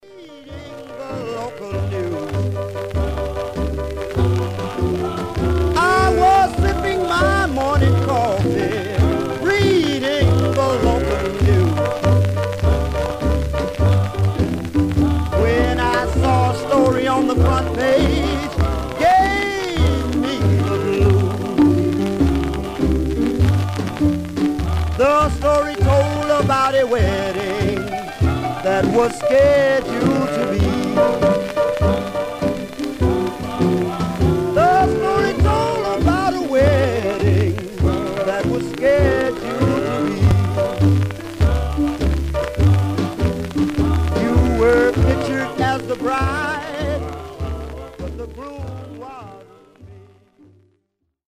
Much surface noise/wear
Mono
Male Black Groups